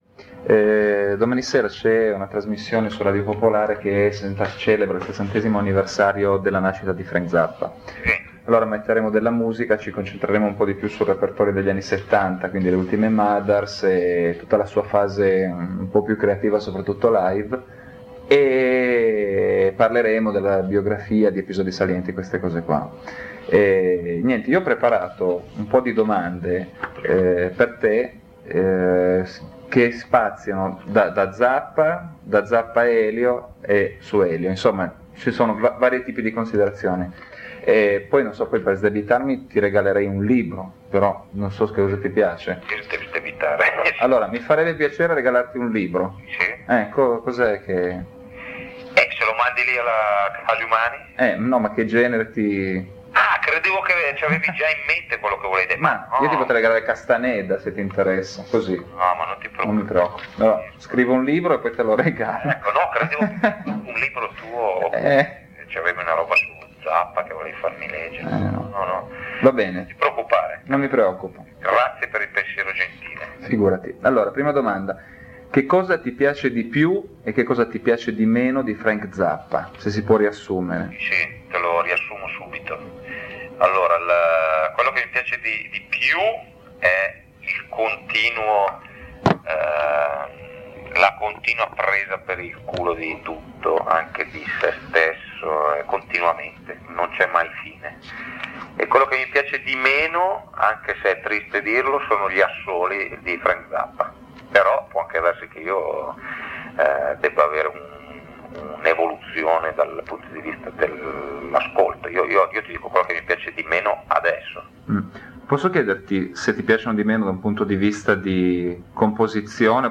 la registrazione di un’intervista fatta ad Elio per una trasmissione